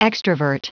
Prononciation du mot extravert en anglais (fichier audio)
Prononciation du mot : extravert